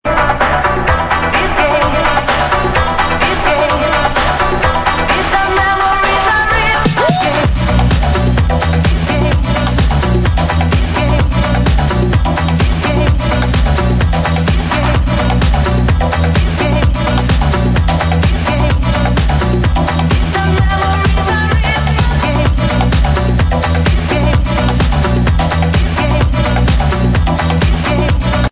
A little (i guess well known) House Tune